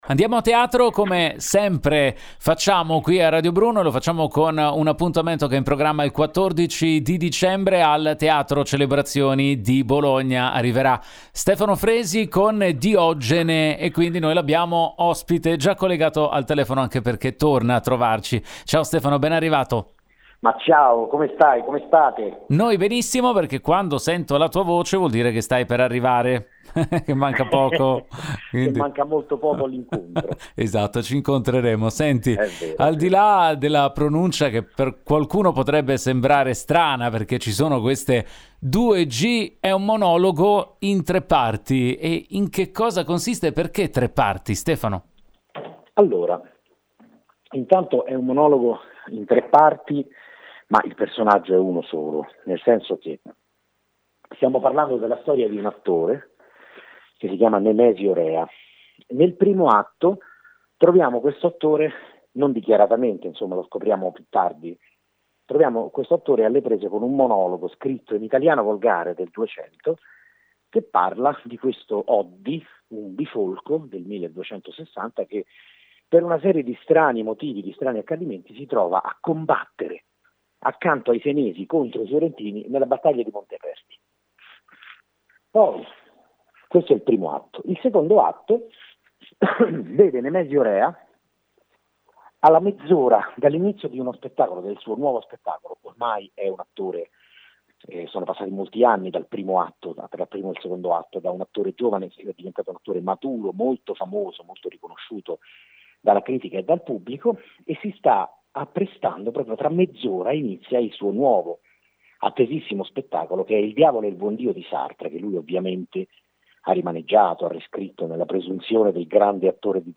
Home Magazine Interviste Stefano Fresi porta in scena “Dioggene” a Bologna